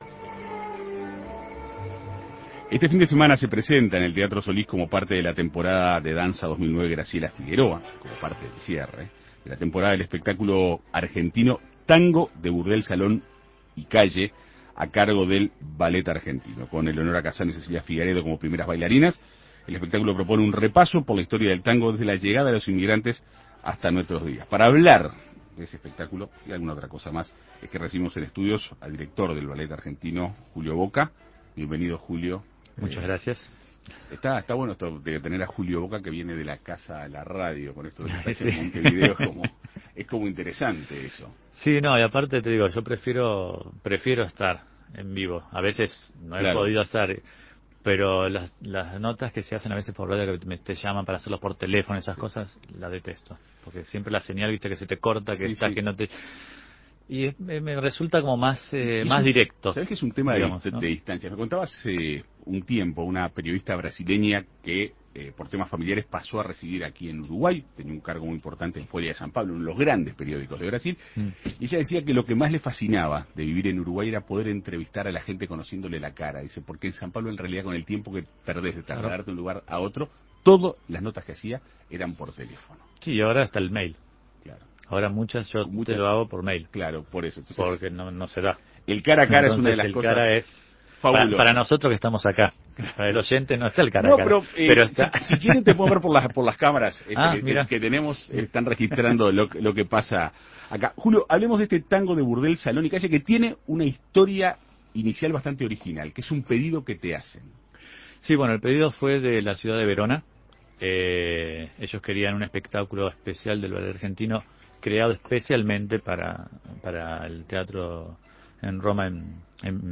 En Perspectiva Segunda Mañana dialogó con el director del Ballet Argentino, Julio Bocca, para conocer detalles del espectáculo.